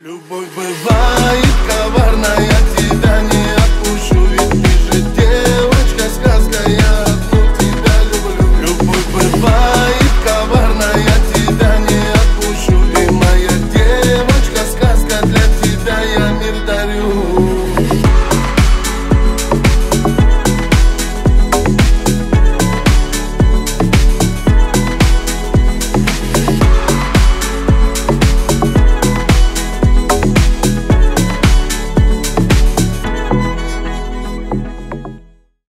Танцевальные
кавказские